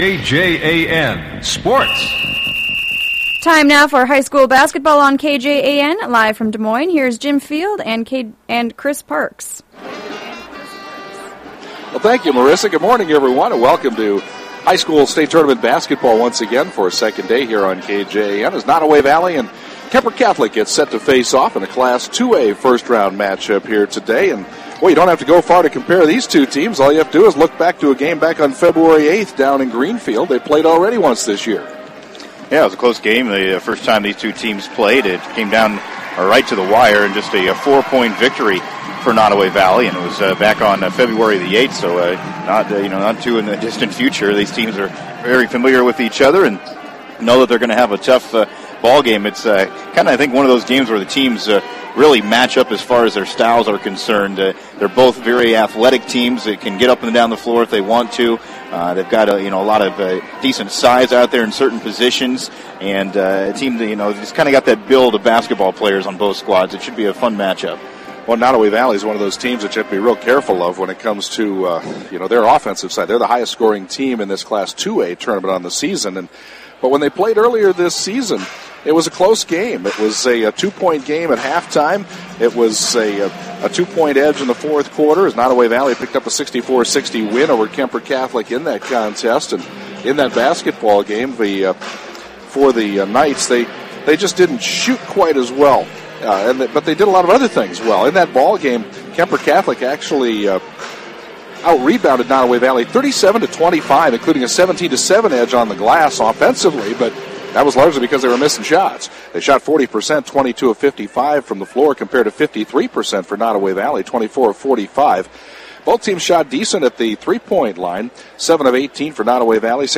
played at Wells Fargo Arena in Des Moines.